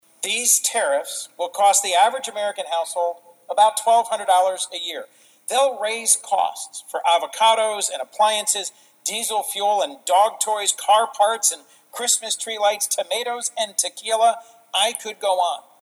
Senator Coons Speaks on Senate Floor to Request Unanimous Consent for Tariff Bill
Senator Chris Coons this afternoon spoke on the Senate floor to request unanimous consent on his bill, the Stopping Tariffs on Allies and Bolstering Legislative Exercise of (STABLE) Trade Policy Act, ahead of the expected implementation of President Trump’s tariffs on Canada and Mexico next week…